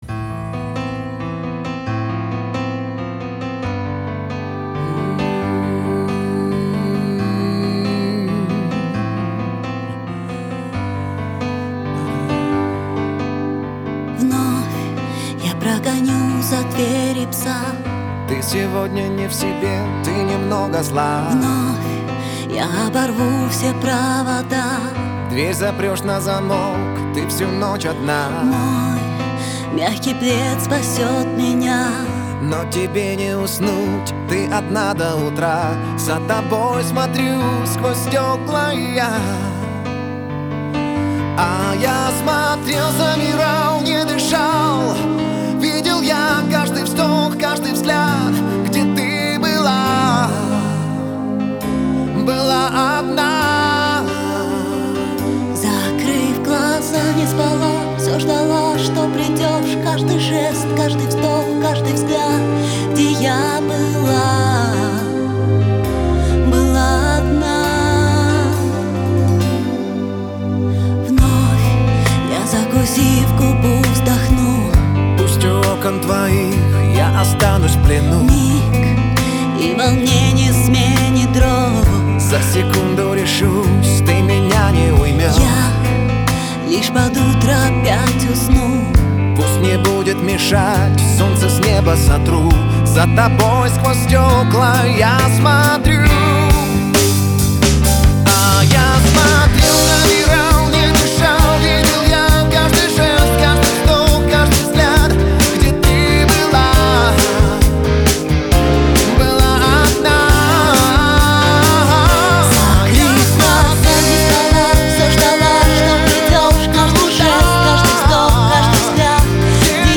两位歌手的声音非常动听，
感情丰富，配合默契。